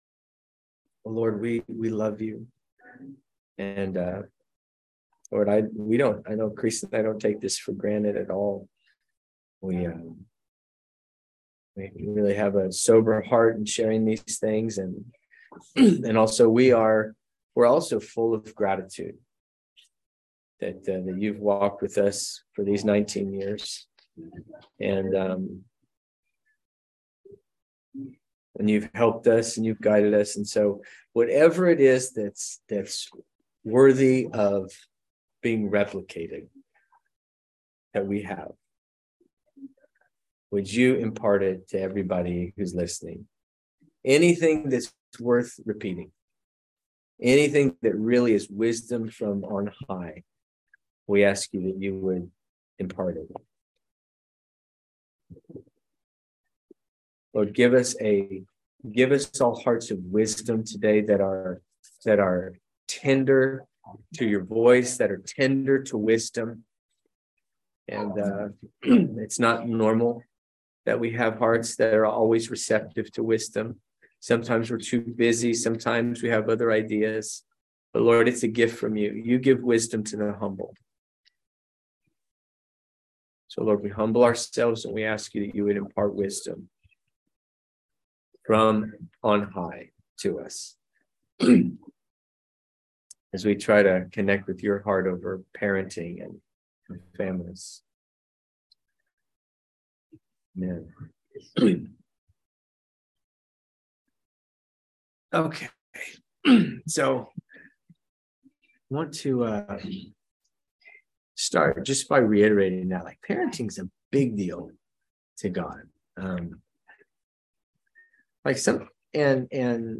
This is seminar or teaching series done